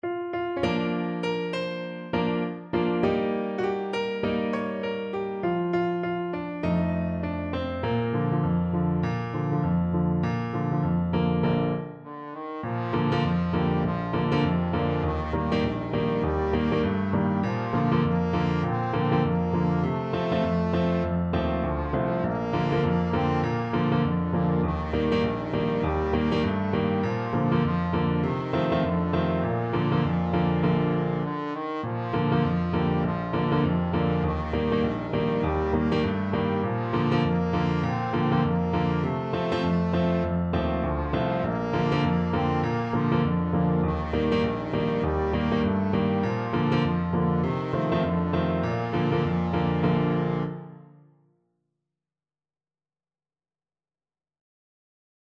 Trombone
Traditional Music of unknown author.
4/4 (View more 4/4 Music)
Bb major (Sounding Pitch) (View more Bb major Music for Trombone )
Swing 16, =100